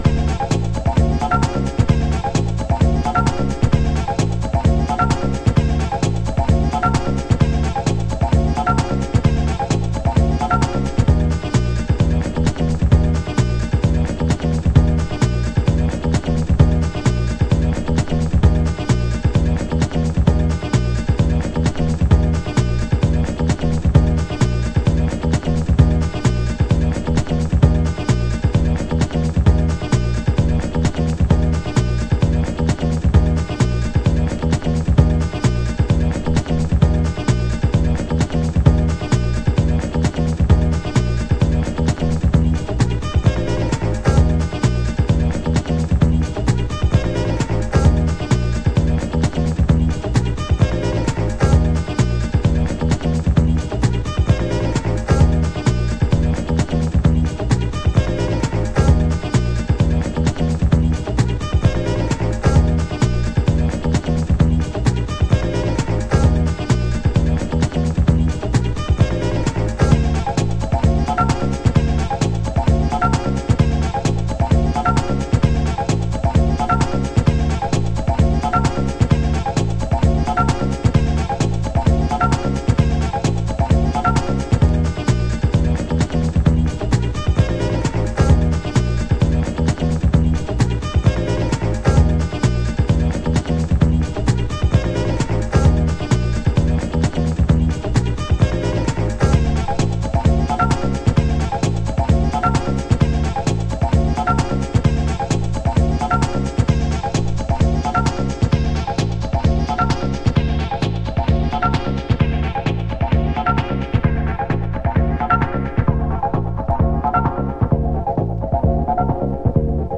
ラテンフュージョンをサンプリング・ループ、サウダージ・フィルターハウス。